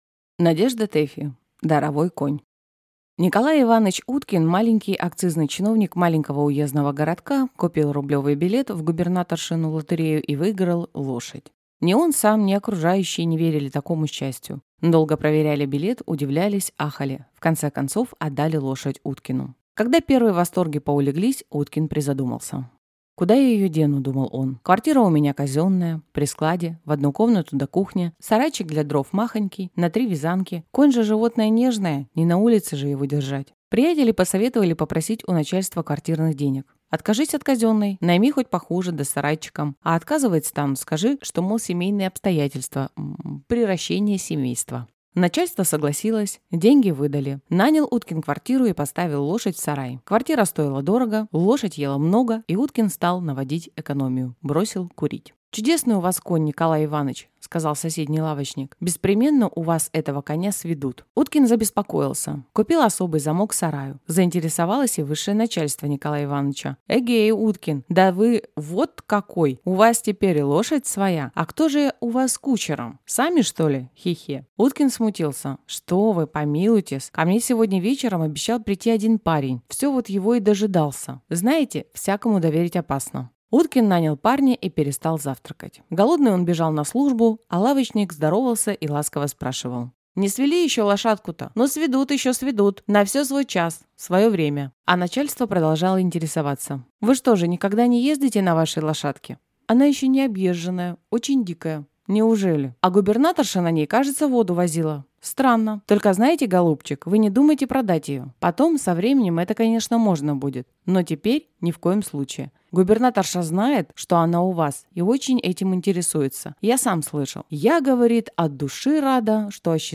Аудиокнига Даровой конь | Библиотека аудиокниг